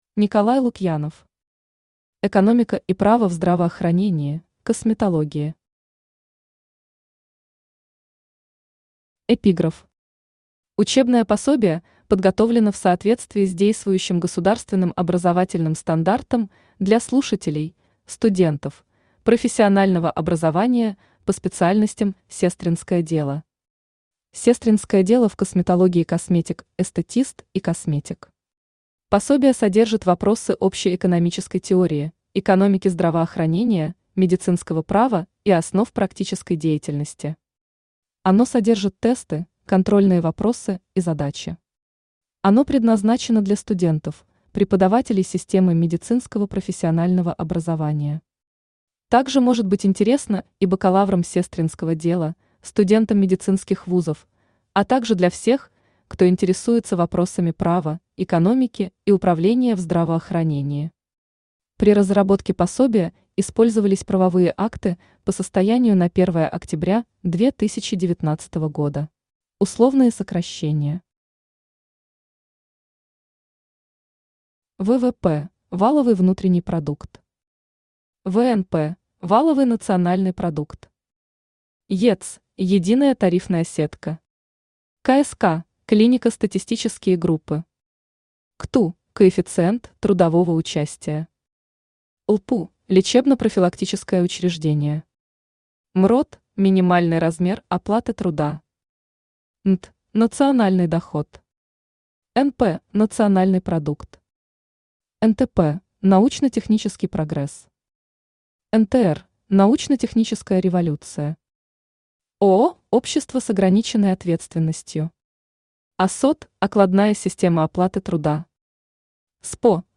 Аудиокнига Экономика и право в здравоохранении (косметологии) | Библиотека аудиокниг
Aудиокнига Экономика и право в здравоохранении (косметологии) Автор Николай Вячеславович Лукьянов Читает аудиокнигу Авточтец ЛитРес.